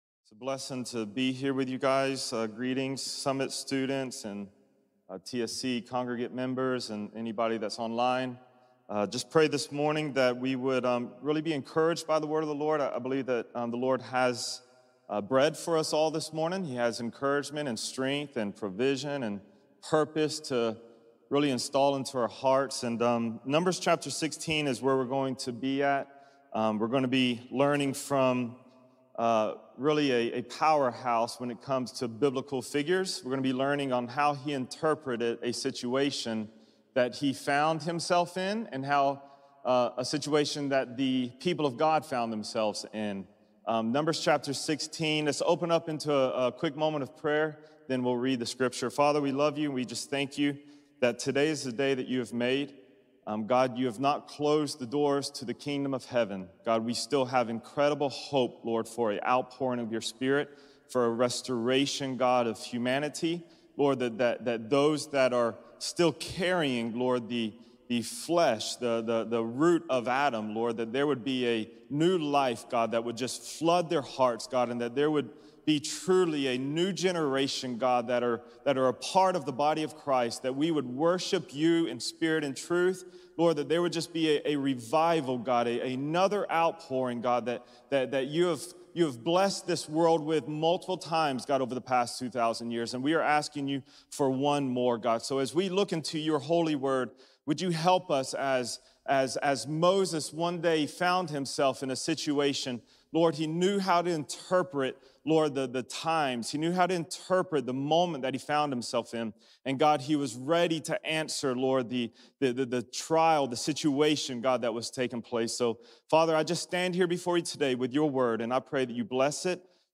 Standing Between The Living And The Dead | Times Square Church Sermons